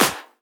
VEC3 Percussion 048.wav